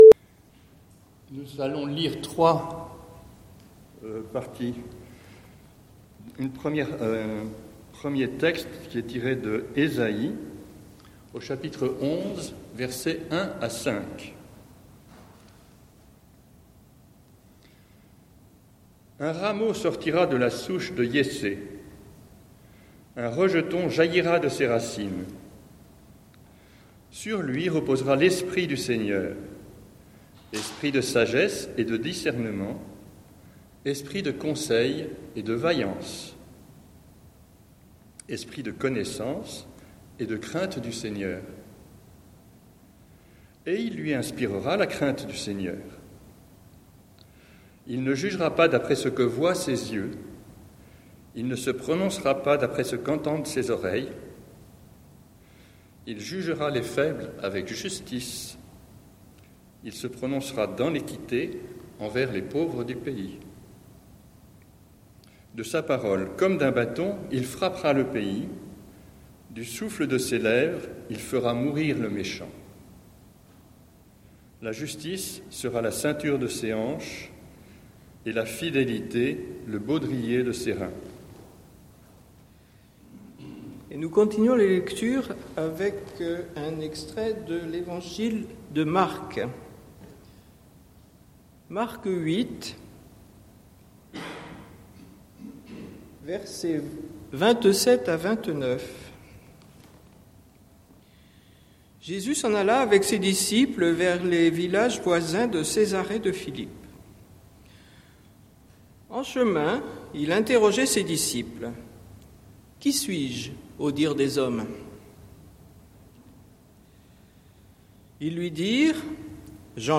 Prédication du 18/11/2018